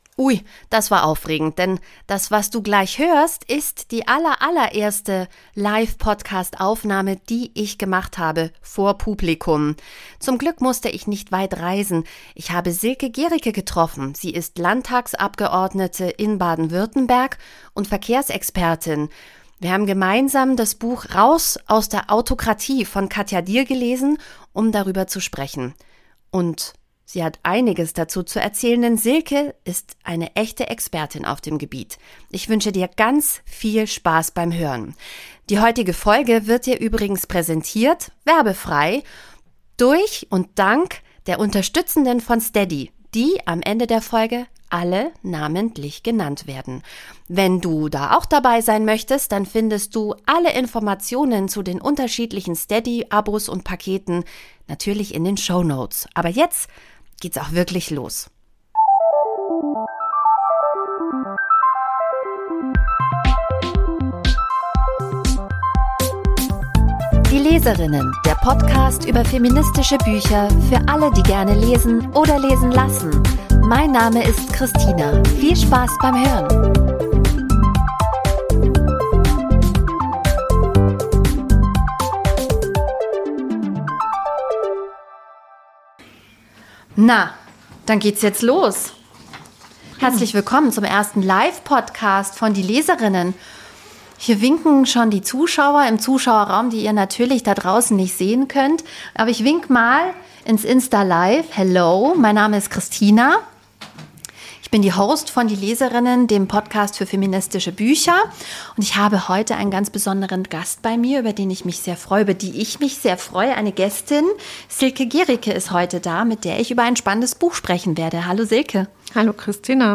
Sie ist verkehrspolitische Sprecherin ihrer Partei im baden-württembergischen Landtag und weiß genau, warum wir immer noch Autos für das Allheilmittel der Fortbewegung halten. Gemeinsam haben wir das Buch "Raus aus der AUTOkratie" von Katja Diehl gelesen und uns vor Publikum getroffen, um darüber zu sprechen. Das Gespräch mit ihr war feministisch, wütend und unglaublich spannend. Es war meine erste Aufnahme vor Publikum und vor lauter Aufregung hab ich direkt vergessen, zu gendern.